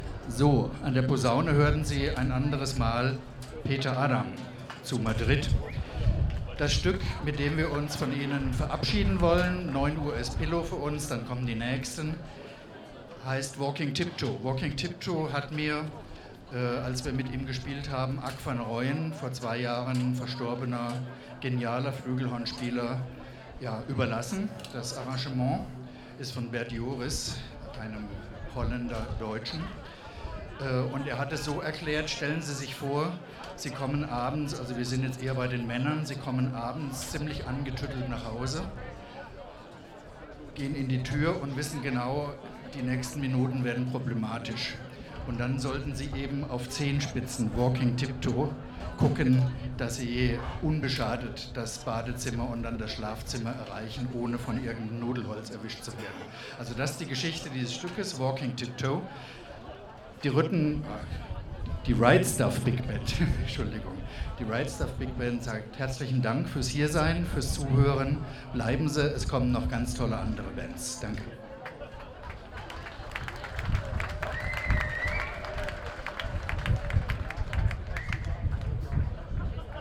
09 - Ansage.mp3